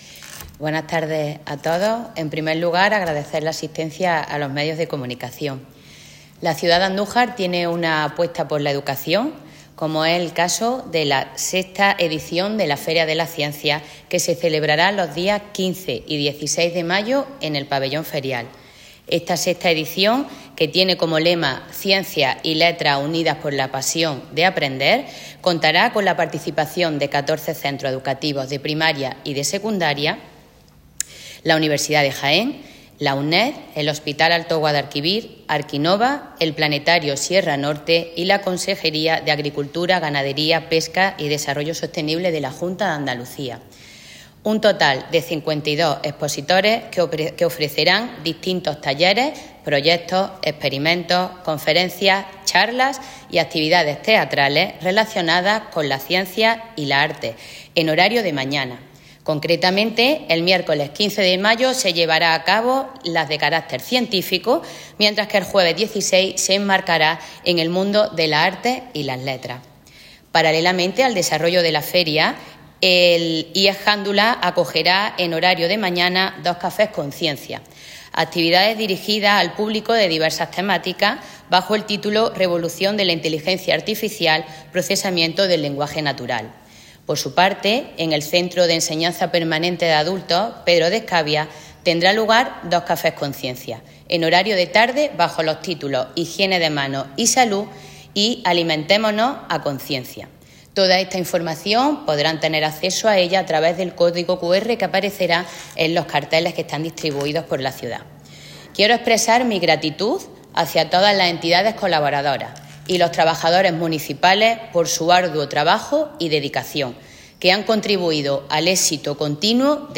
La concejala de Educación de Andújar, Azucena Cepedello, ha presentado este lunes la sexta edición de la Feria de las Ciencias, organizada por el Ayuntamiento de Andújar y que contará con un total de 52 expositores y la participación de 14 centros de Educación Primaria y Secundaria, las universidades de Jaén (UJA) y Nacional de Educación a Distancia (UNED), el Hospital Alto Guadalquivir, la Escuela Oficial de Idiomas (EOI), el Club de Aeromodelismo de Andújar, ARQinnova, el Planetario Sierra Norte y la Junta de Andalucía.
“Desde el Ayuntamiento de Andújar seguiremos con el compromiso de fomentar el talento de los estudiantes a través de iniciativas que los acerquen al conocimiento de los ámbitos humanístico y científico”, ha resaltado en rueda de prensa la concejala, que ha agradecido a las entidades colaboradoras su aportación y los trabajadores municipales su trabajo y dedicación, que ha contribuido al éxito de esta feria.